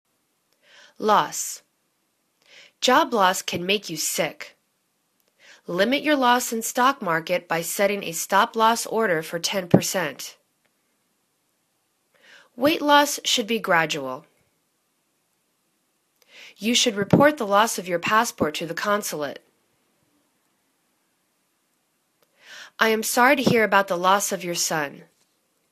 loss     /laws/    n